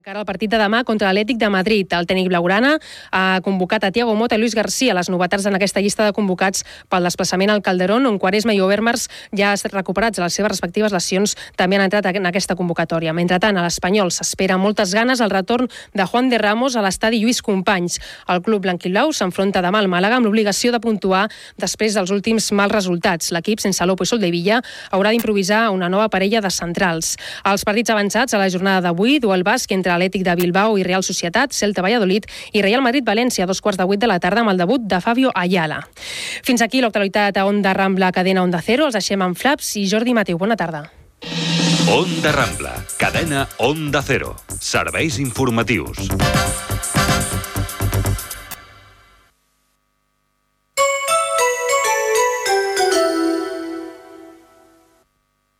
Esports, careta de sortida del programa, sintonia de l'emissora
Informatiu
FM